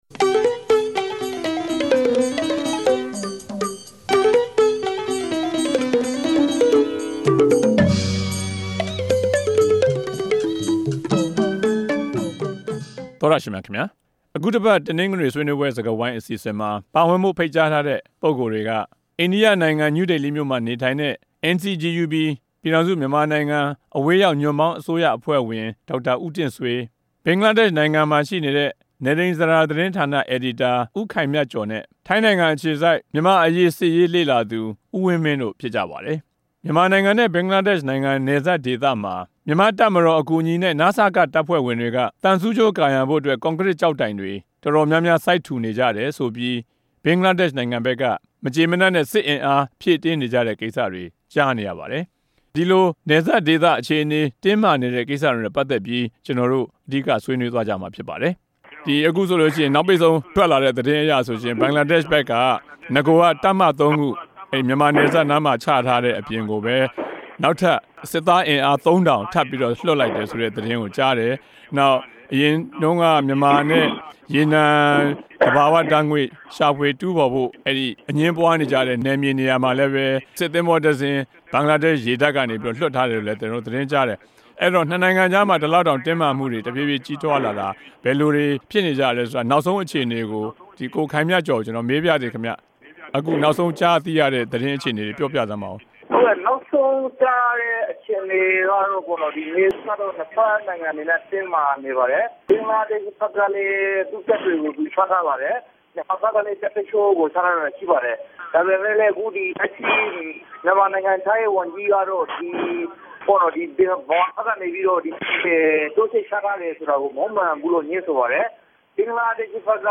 အခုတပတ် တနဂဿေိံြ ဆြေးေိံြးပြဲ စကားဝိုင်းမြာ ူမန်မာနဲႚ ဘဂဿလားဒေ့ြရ် နယ်စပ်မြာ ူမန်မာဘက်က သံဆူးဋ္ဌကိြးကာရံဘိုႛ ကြန်ကရစ်တိုင်တြေ စိုက်ထူန္ဘေပီး ိံြစ်ဖက်စစ်တပ်တြေ ပိုမို အားူဖည့်နေုကတဲ့အတြက် တင်းမာမြတြေ ပေၞပေၝက်နေတာနဲႛ ပတ်သက်္ဘပီး ဆြေးေိံြးထားုကပၝတယ်။
တနဂဿေိံစြကားဝိုင်း။